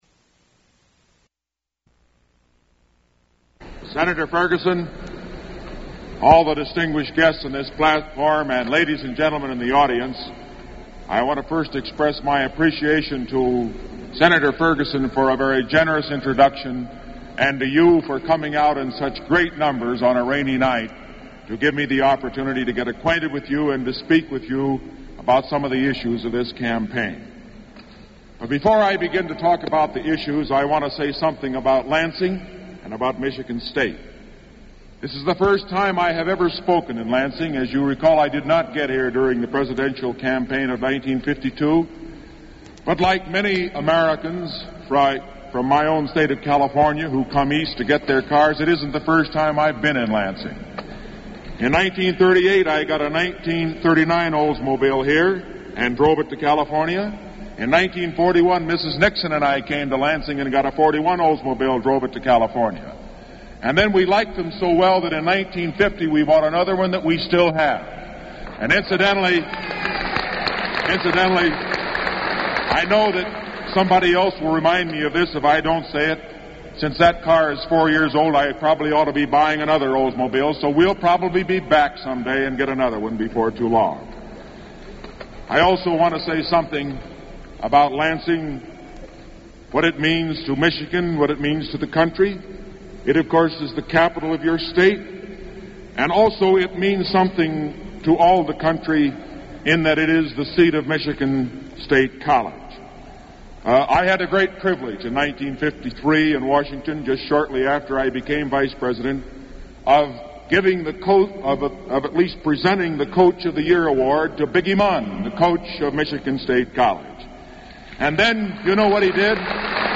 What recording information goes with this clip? Speech presented under the auspices of the Republican State Central Committee at Lansing, Michigan